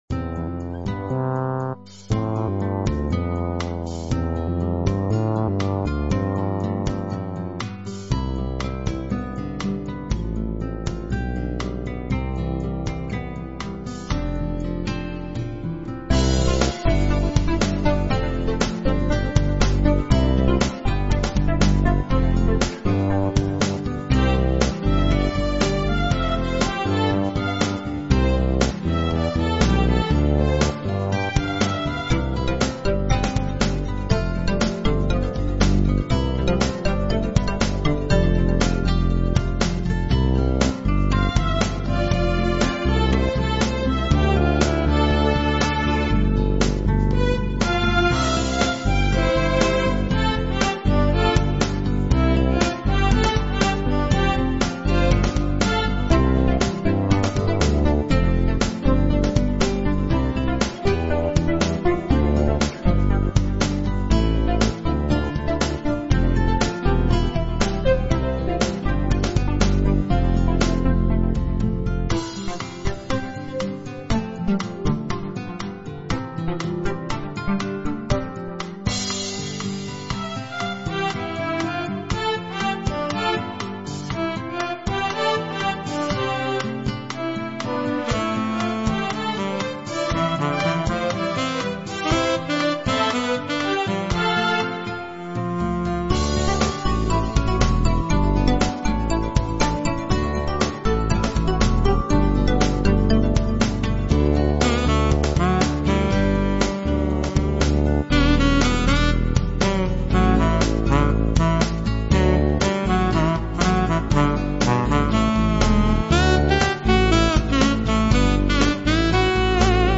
Relaxed and Joyful Instrumental Pop